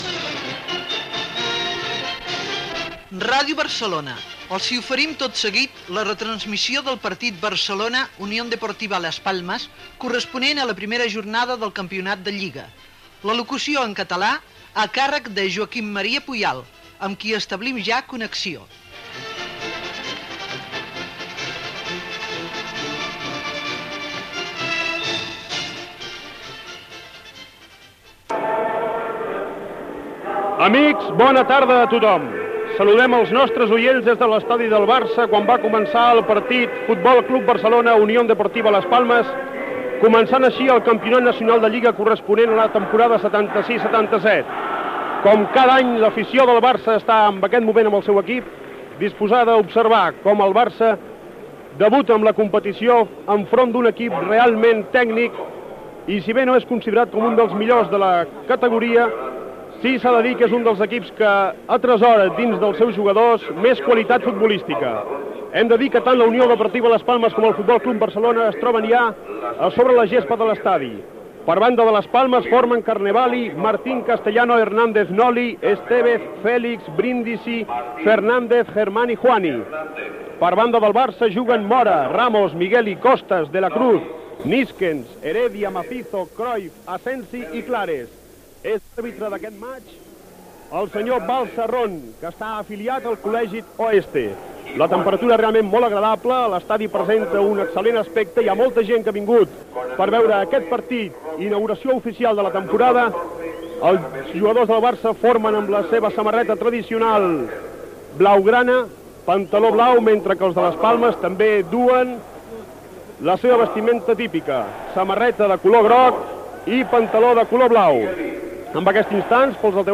Presentació del partit de la lliga masculina de futbol entre el F.C. Barcelona i la Unión Deportiva Las Palmas. Salutació, ambient al camp i aliniació dels dos equips.
Narració del quart gol del Barça fet per Cruyff.
Esportiu